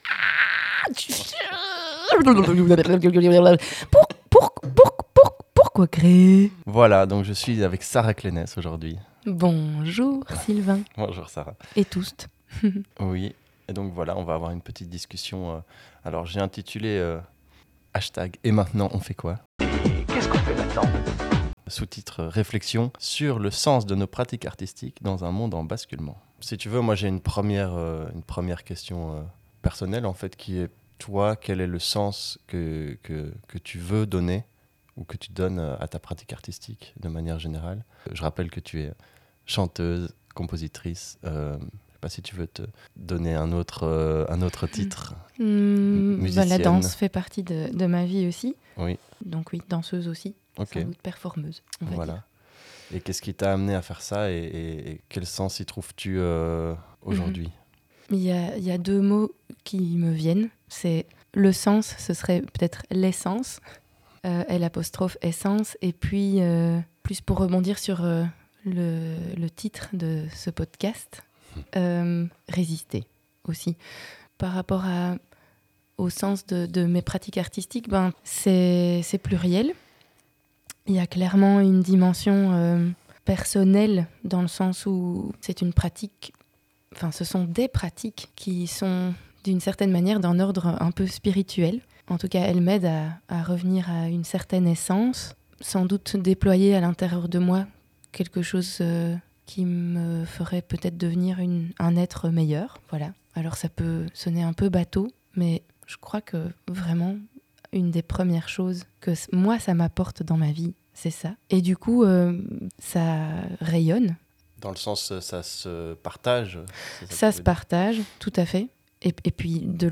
Grâce à l’aide technique de la Jazz Station et de leur studio Jazz Lines, j’ai donc enregistré ces discussions sous forme de podcasts, avec un.e invité.e à chaque fois.